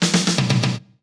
British SKA REGGAE FILL - 10.wav